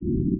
The original sound is a low frequency noise (cut-frequency at 780Hz).
(Virtual source at -90°)
Basic decoding LF noise_o1_basic